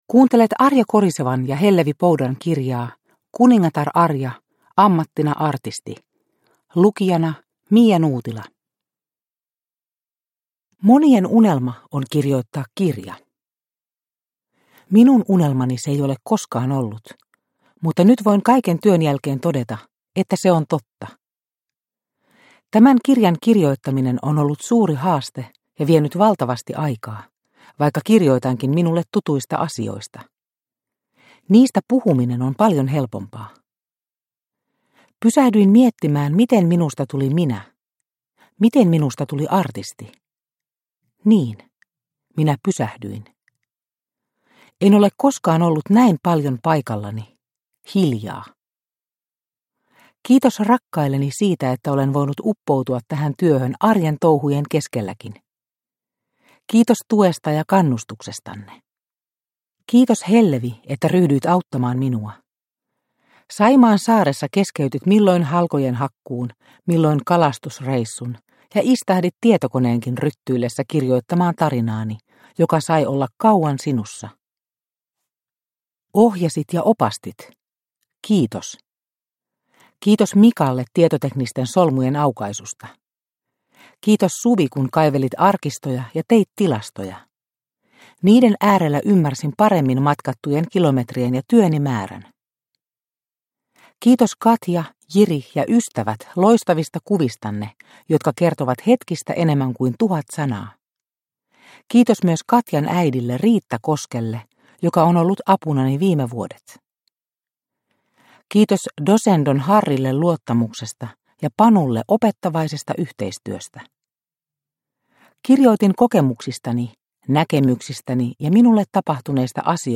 Kuningatar Arja – Ljudbok – Laddas ner